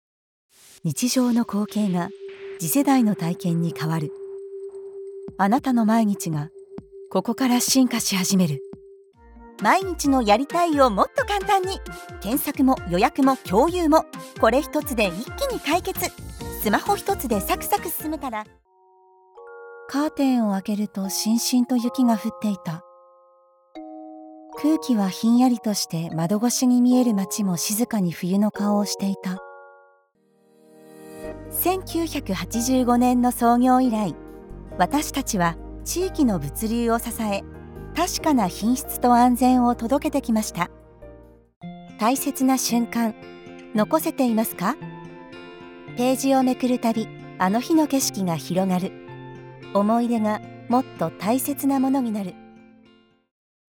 Entreprise
Authentique
Articuler